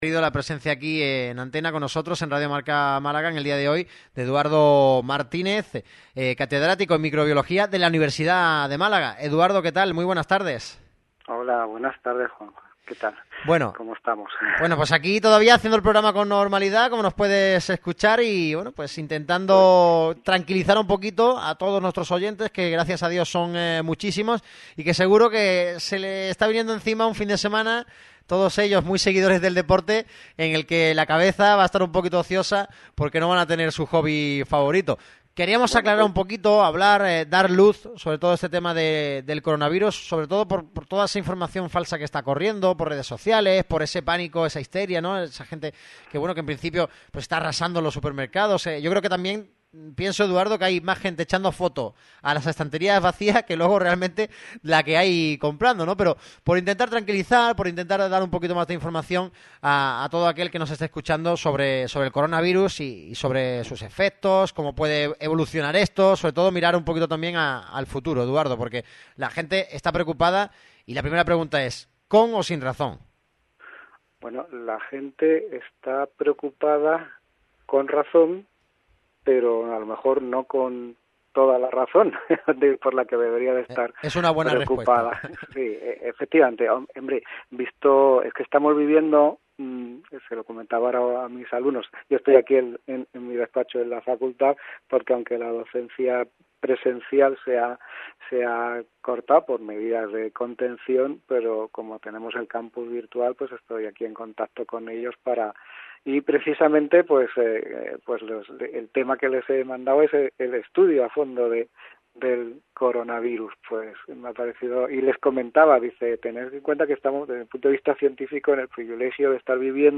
ESCUCHA LA ENTREVISTA COMPLETA EN RADIO MARCA MÁLAGA